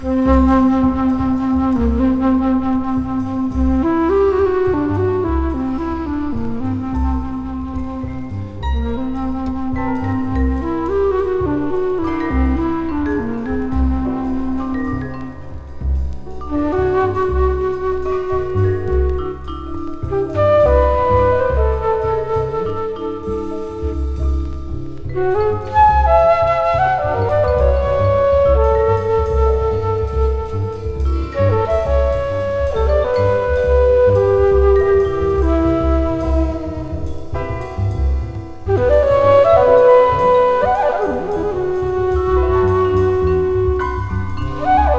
クールとモーダルと神秘の三拍子を揃った、様々な角度から音楽を楽しみ感じさせる1枚。
Jazz, Classical, Modal　USA　12inchレコード　33rpm　Mono